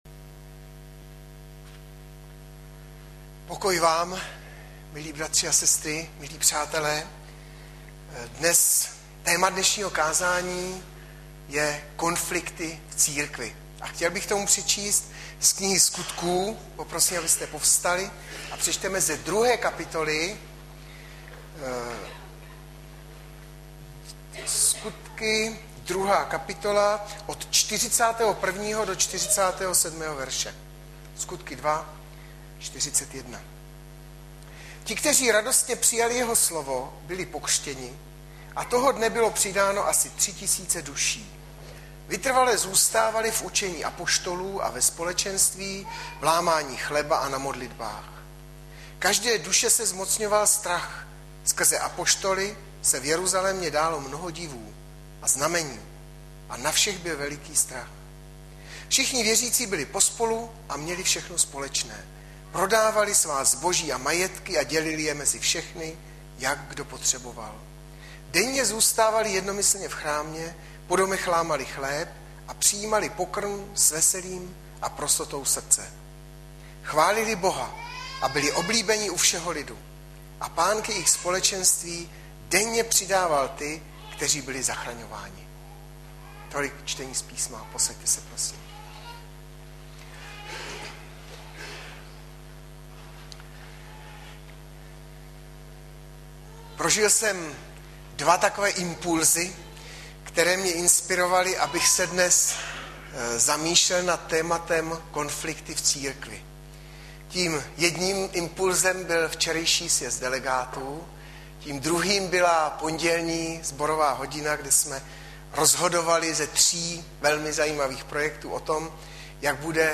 Hlavní nabídka Kázání Chvály Kalendář Knihovna Kontakt Pro přihlášené O nás Partneři Zpravodaj Přihlásit se Zavřít Jméno Heslo Pamatuj si mě  22.04.2012 - KONFLIKTY V CÍRKVI - Sk 2,41-47 Audiozáznam kázání si můžete také uložit do PC na tomto odkazu.